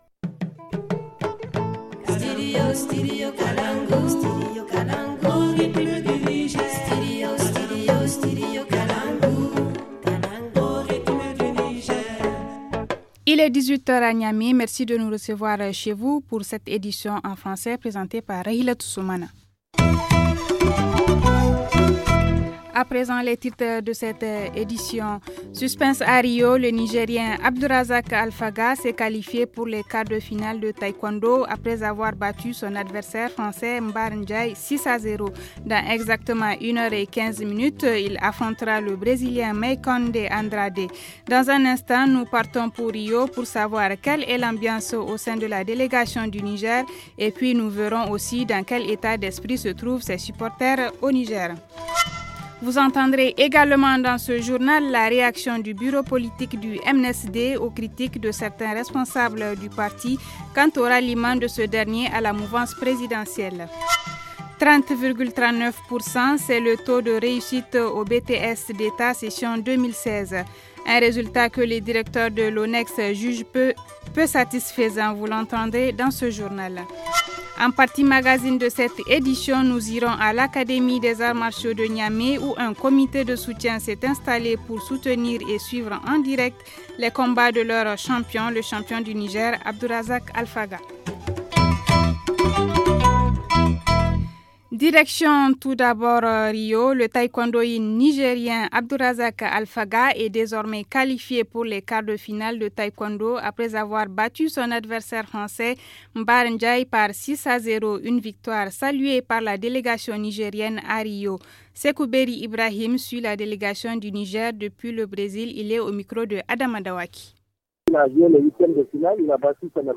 Vous l’entendrez dans ce journal.